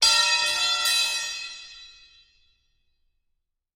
лом упал на бетон боком